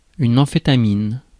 Ääntäminen
Synonyymit amphé speed amphét Ääntäminen France Tuntematon aksentti: IPA: /ɑ̃.fe.ta.min/ Haettu sana löytyi näillä lähdekielillä: ranska Käännös Substantiivit 1. amfetamiini Suku: f .